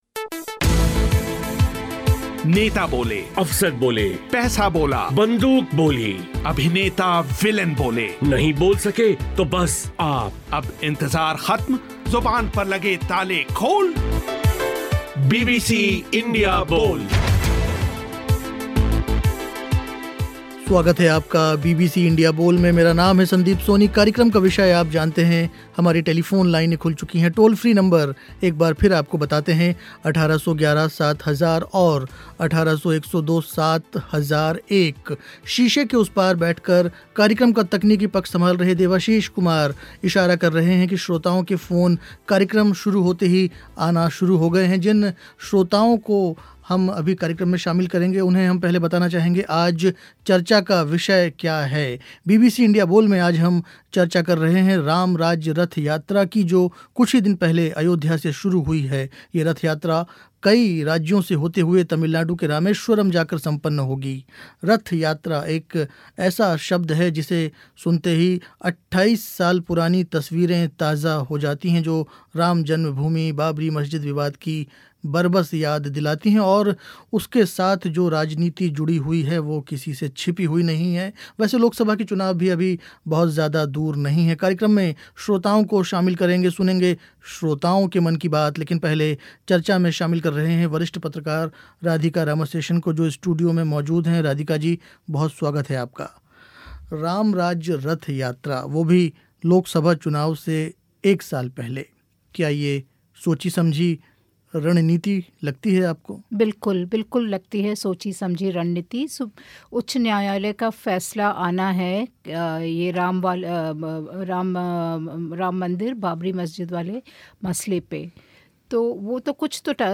और श्रोताओं ने पूछे सवाल.